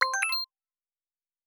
Special & Powerup (56).wav